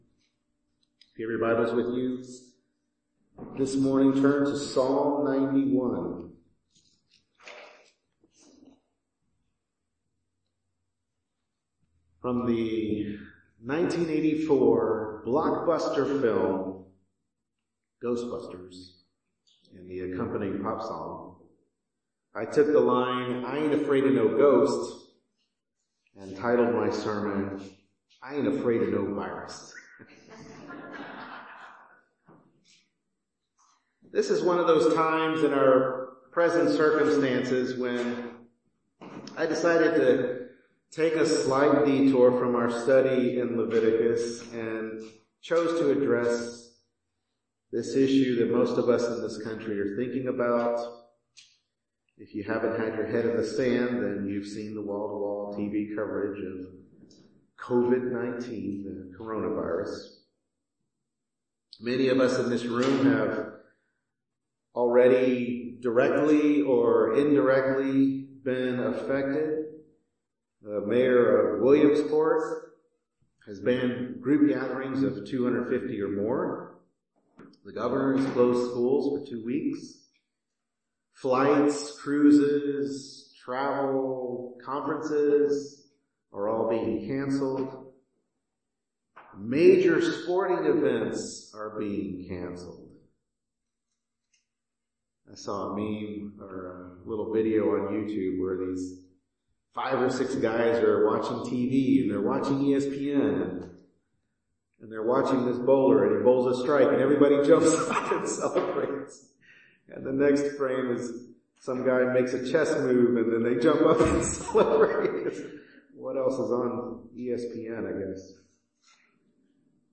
Psalm 91 Service Type: Morning Worship Service Bible Text